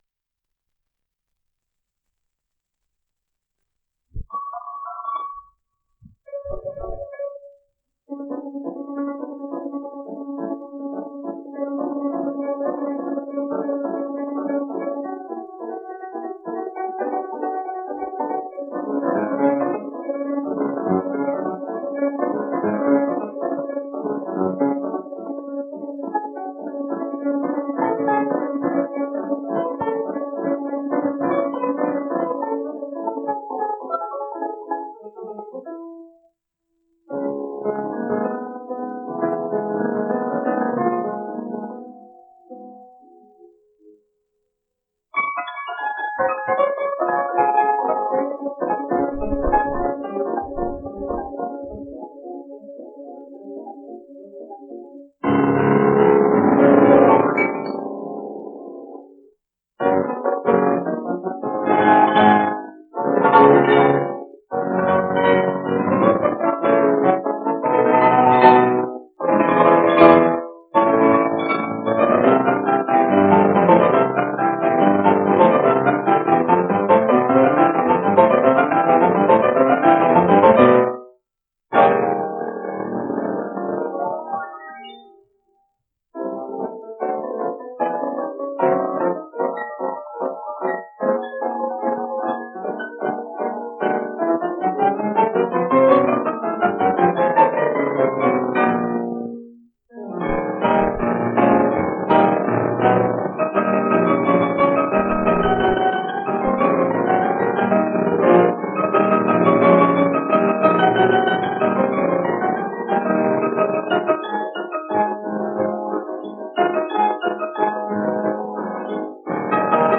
2 discos : 78 rpm ; 30 cm Intérpretes